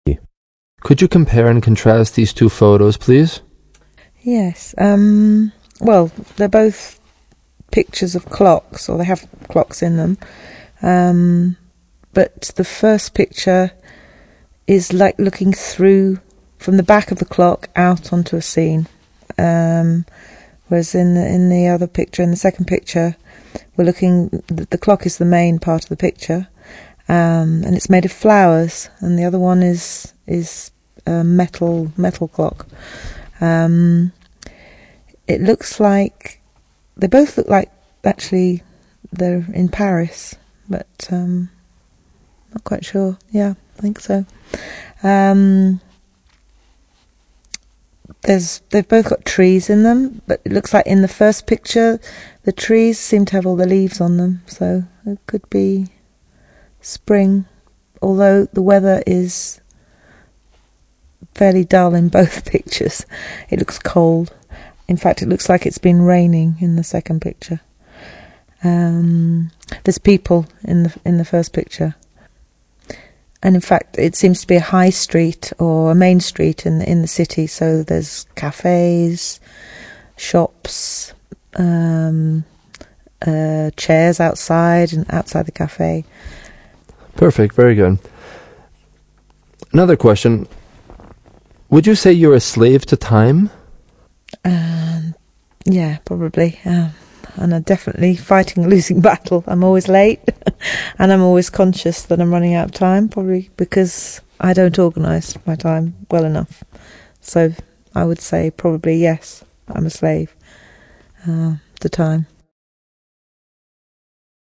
picture description clocks.mp3